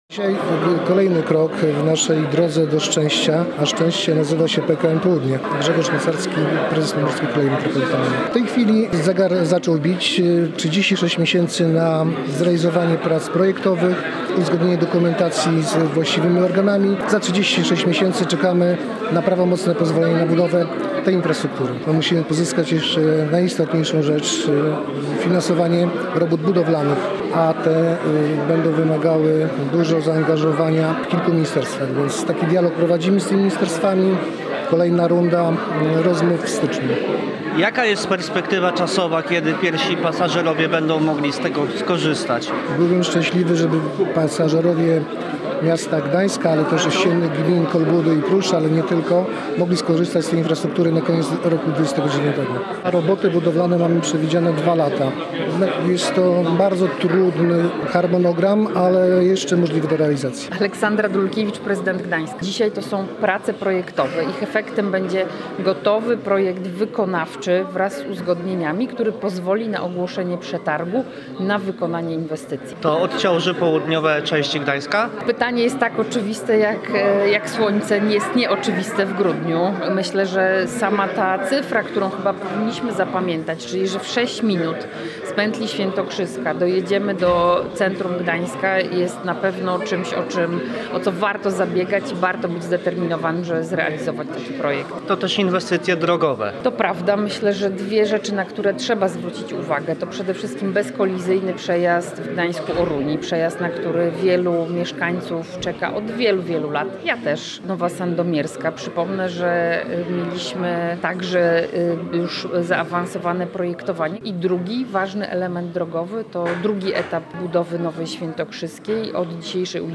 Posłuchaj materiału naszego reportera: https